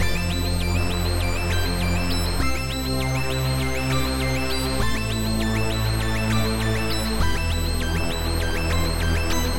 硬朗的嘻哈舞蹈合成器，带有低音和高度的循环。
描述：一个硬的嘻哈/舞蹈循环
标签： 100 bpm Hip Hop Loops Synth Loops 1.62 MB wav Key : Unknown
声道立体声